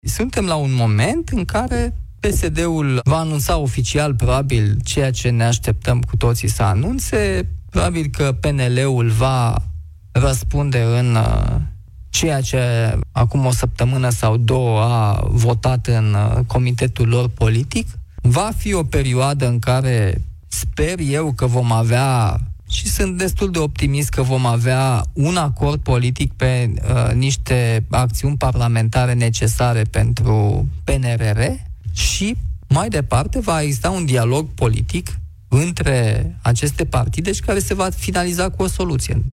Președintele Nicușor Dan, la Europa FM: „Va fi o perioadă în care, sper eu, vom avea un acord politic pe niște acțiuni parlamentare necesare pentru PNRR”